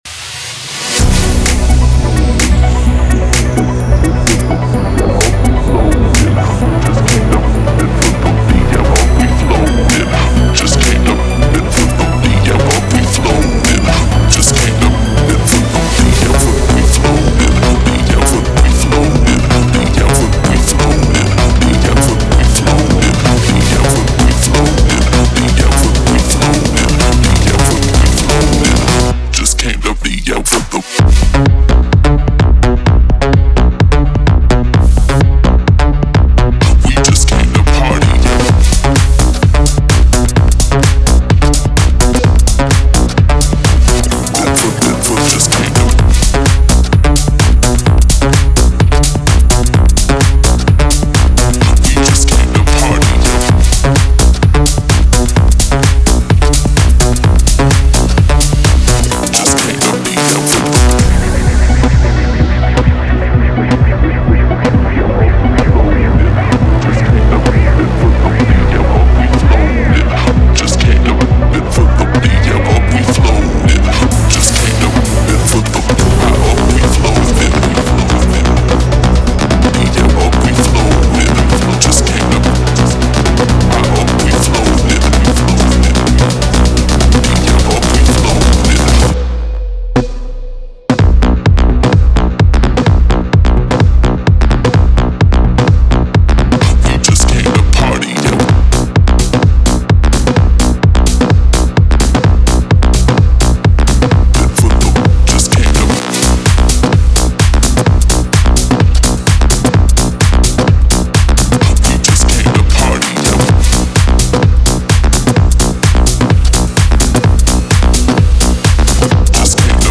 Type: Serum Samples
Drum & Bass Dubstep Electro House Hardcore / Hardstyle House Synthwave / Retrowave Tech House Techno Trance
Packed with high-quality Melodic House samples and presets, it's a must-have for producers. Explore punchy drums, infectious basslines, mesmerizing synths, and atmospheric soundscapes.